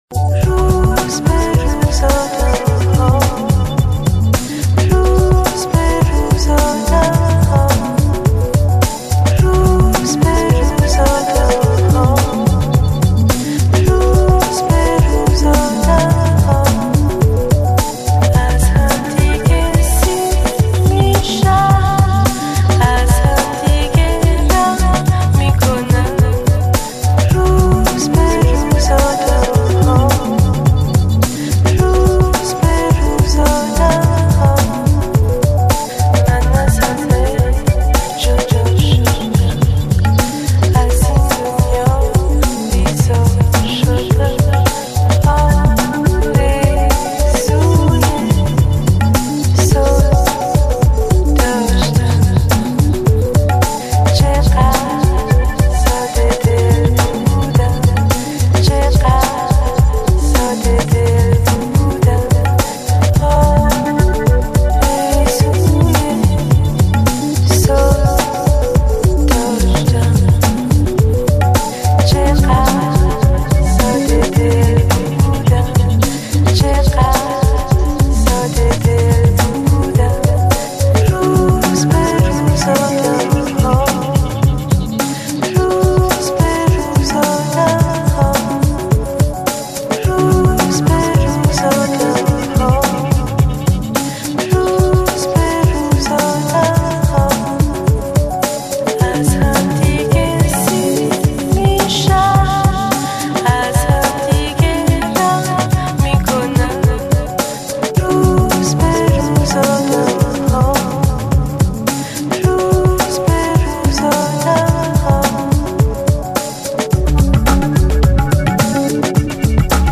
甜美中氤蕴迷幻，舒适解压的聆听旅程。
Bossa Nova，Downtempo，Nu Jazz 与 Lounge